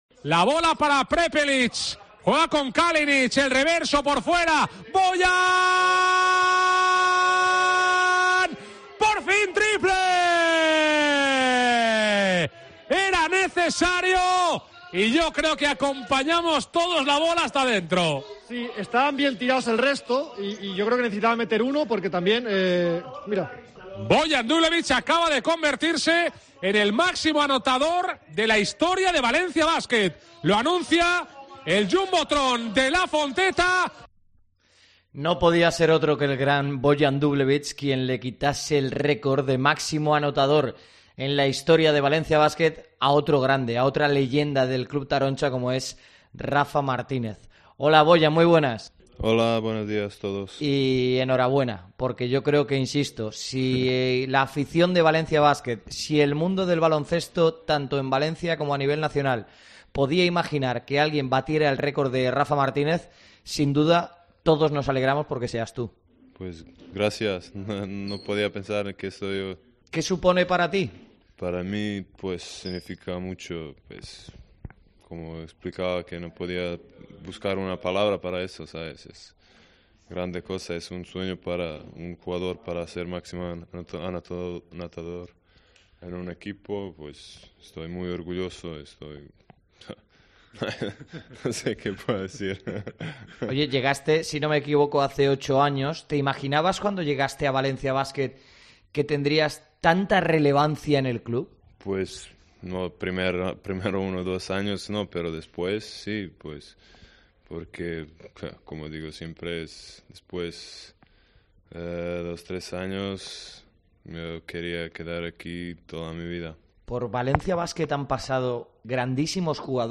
ENTREVISTA COPE
El capitán de Valencia Basket atiende a COPE en su primera entrevista tras convertirse en el máximo anotador del equipo y se emociona escuchando a "su hermano" Rafa Martínez